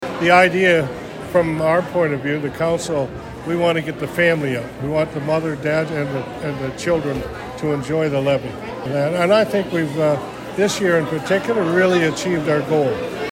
Mayor Jim Harrison says it is important for residents to be able to meet and chat with their council in a more relaxed setting.